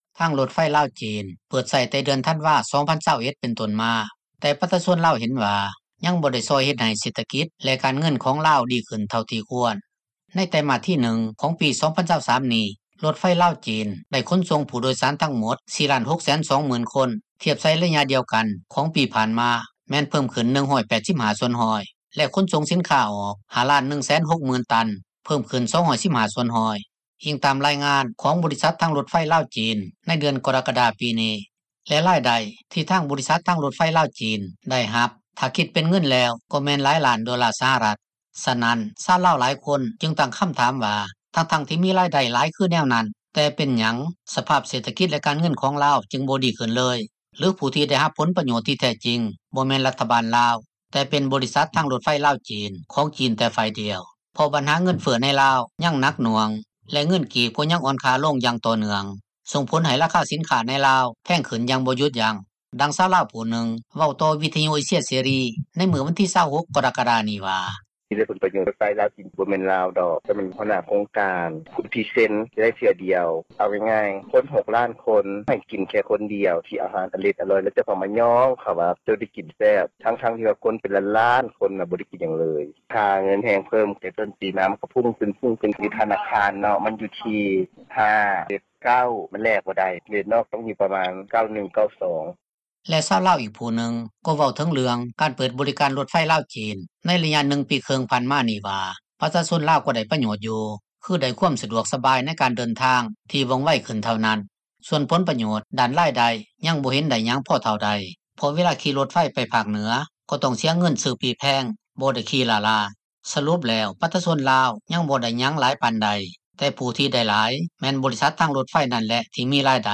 ດັ່ງຊາວລາວຜູ້ນຶ່ງ ເວົ້າຕໍ່ວິທຍຸ ເອເຊັຽ ເສຣີ ໃນມື້ວັນທີ 26 ກໍຣະກະດານີ້ວ່າ: